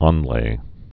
(ŏnlā, ôn-)